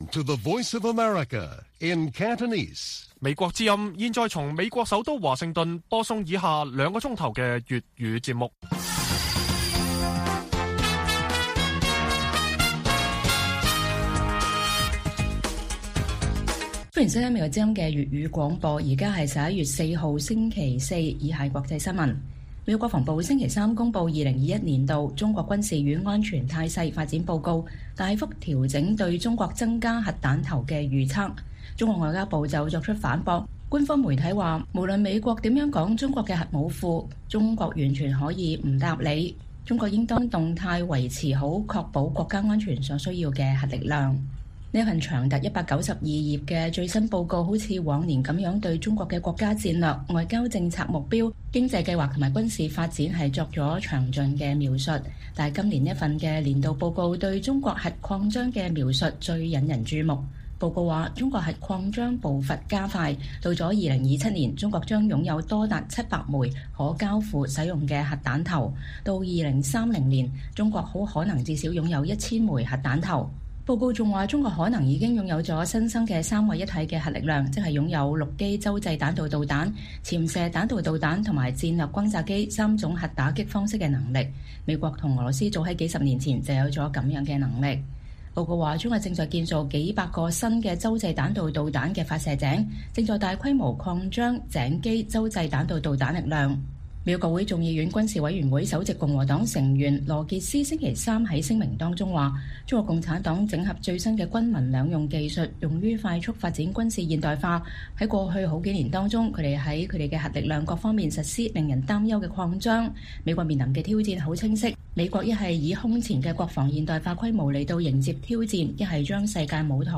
粵語新聞 晚上9-10點: 五角大樓指中國2030年或擁有1000枚核彈頭